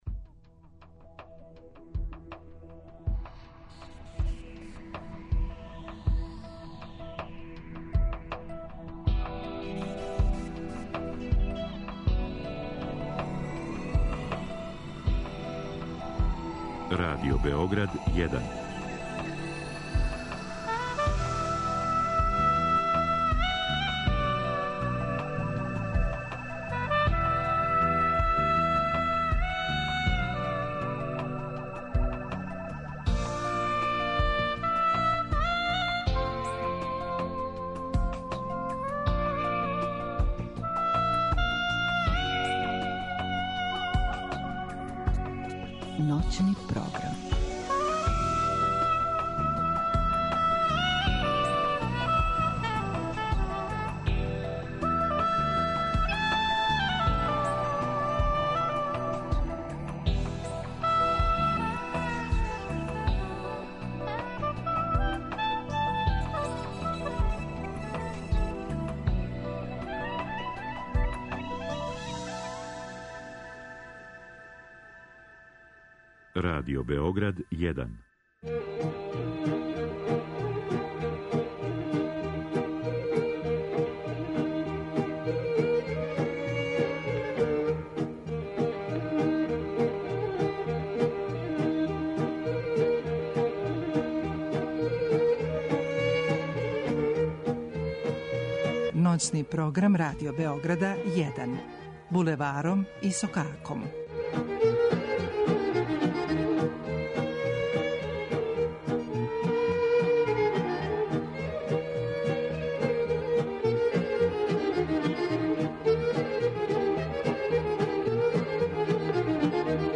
Уживаћемо и у „АСТАЛСКОМ ПЕВАЊУ" предратних певача, чији је представник био чувени Мијат Мијатовић...
Радио Београд 1, 00.05